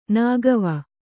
♪ nāgava